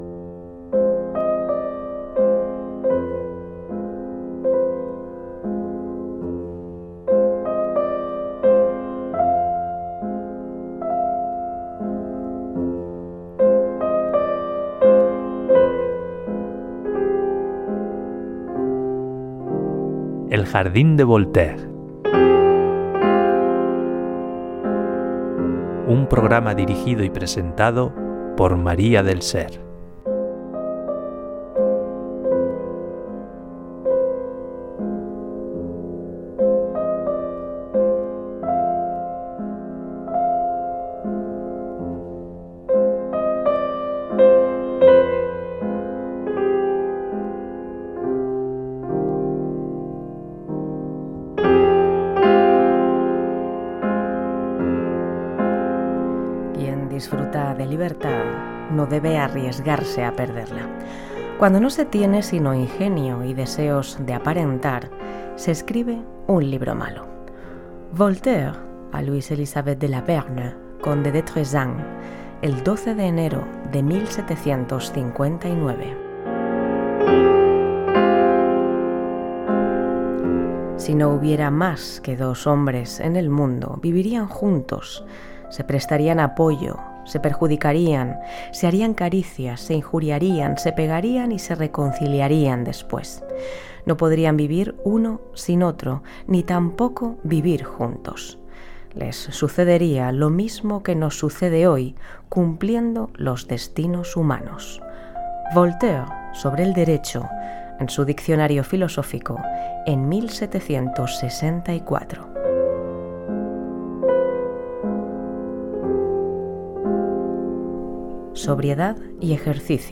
Careta del programa, cites de l'escriptor Voltaire (Francois Marie Arouet) i tema musical
Musical